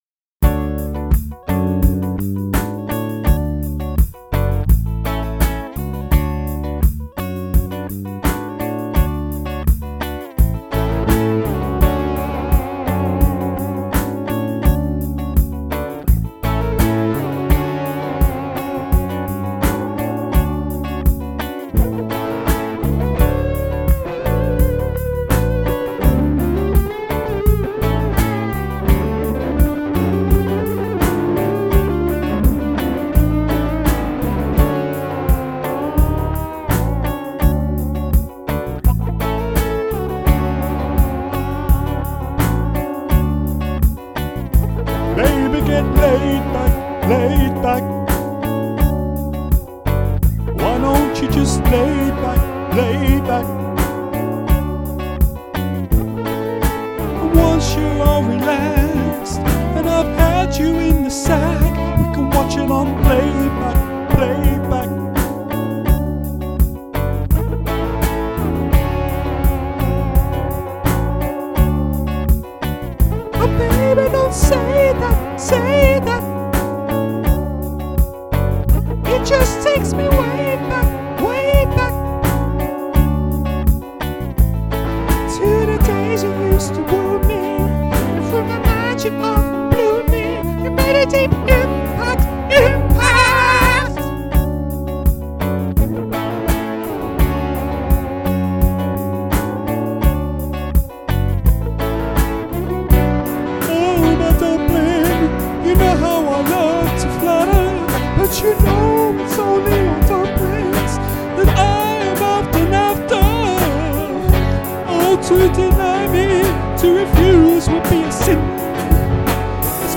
We've done a terrifyingly powerful song today.